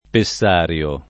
vai all'elenco alfabetico delle voci ingrandisci il carattere 100% rimpicciolisci il carattere stampa invia tramite posta elettronica codividi su Facebook pessario [ pe SS# r L o ] s. m. (med.); pl. ‑ri (raro, alla lat., ‑rii )